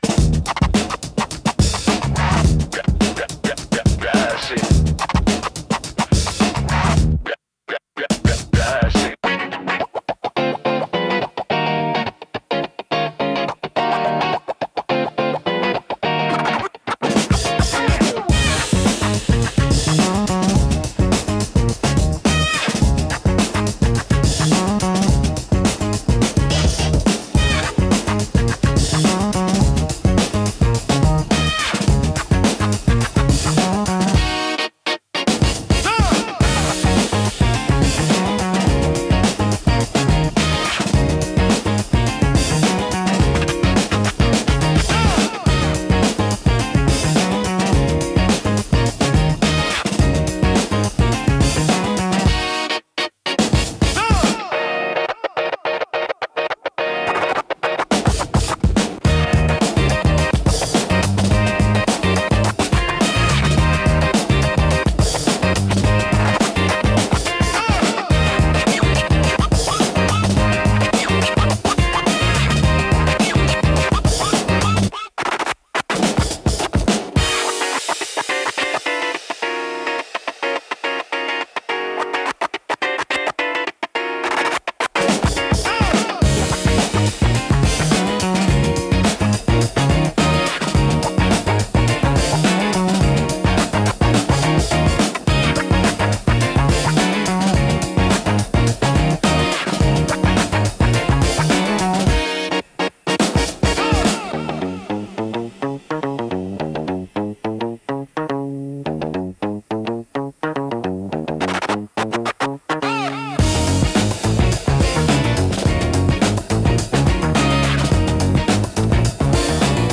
若々しく自由なエネルギーを感じさせ、都会的でポップな空間作りや動画配信の明るい雰囲気づくりに効果的です。